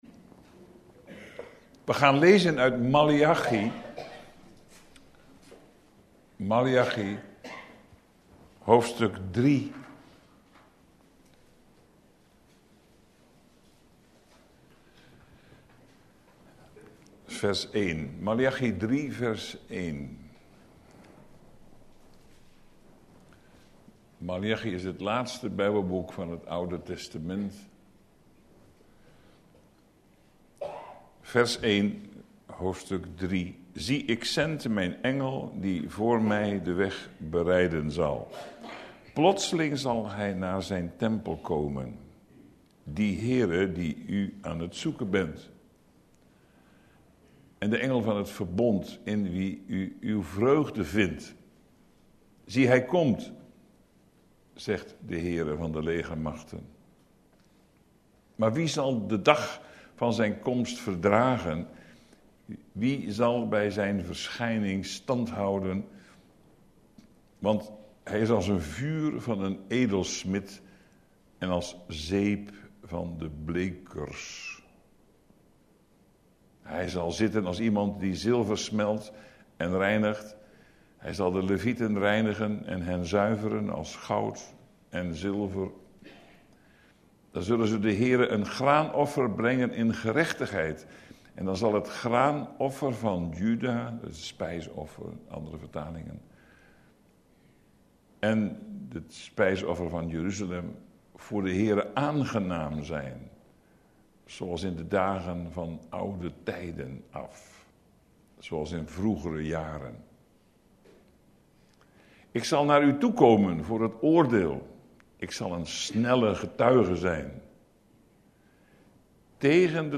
AfspelenDeze preek is onderdeel van de serie:"De profeet Maleachi"DownloadAudiobestand (MP3)